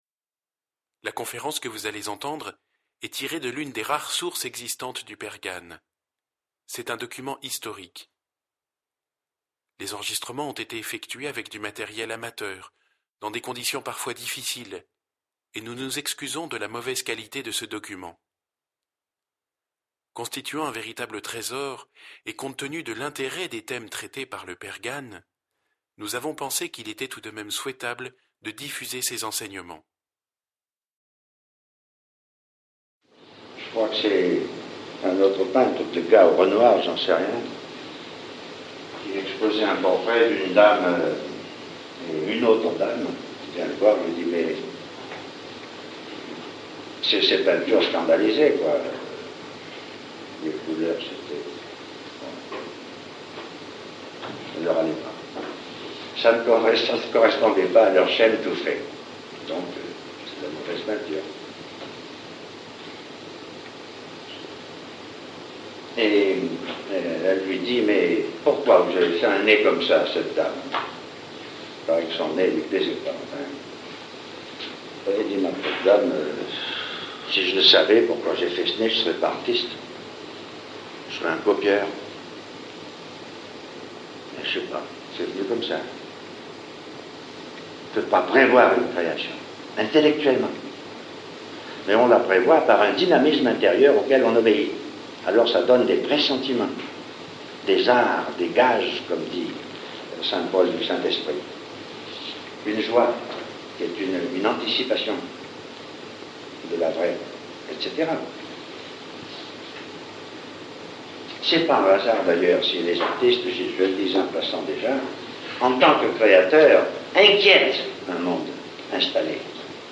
Enseignement
Cet ensemble de 6 conférences est un document historique. Enregitré avec des moyens amateurs, il contitue un véritable trésor de la théologie du XXème siècle. Même si l'écoute est parfois difficile du fait des mauvaises conditions d'enregistrements, il nous a paru important de partage ce trésor.